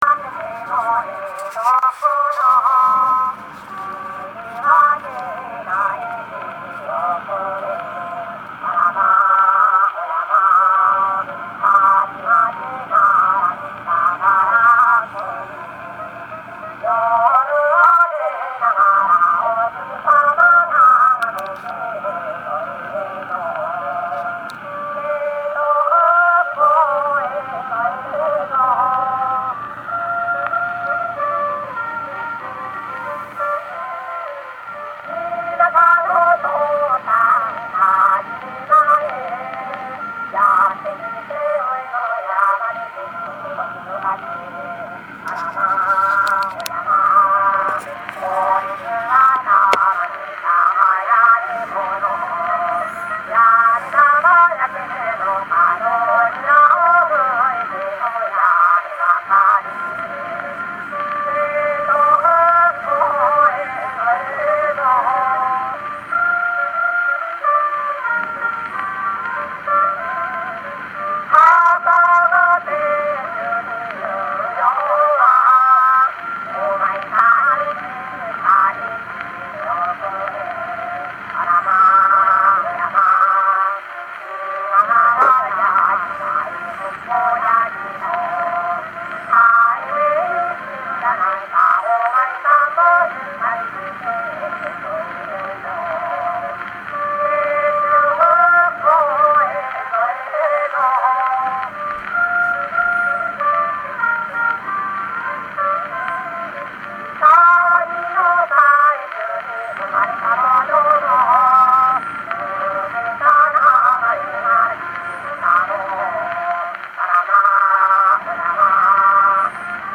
単純で歌い易い曲ではあるが住む場所にしがみついても生きようとする庶民の心意気が伝わってくる。